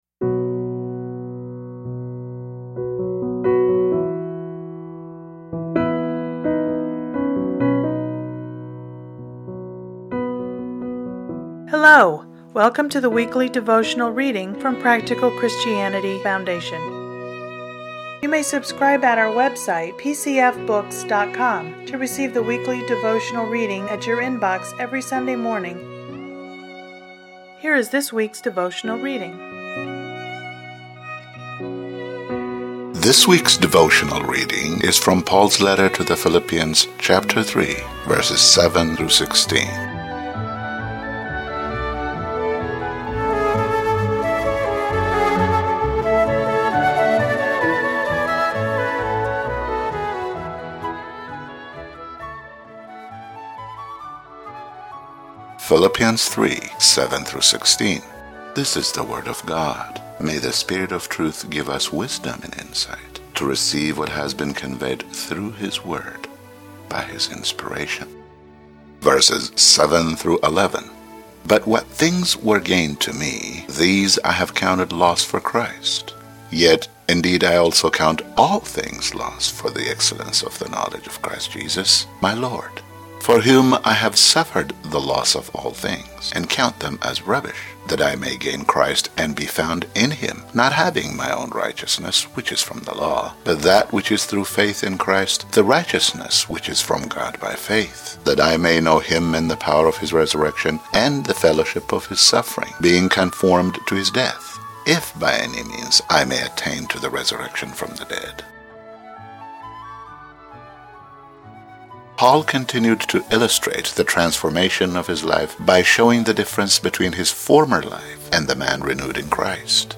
Listen to today's devotional commentary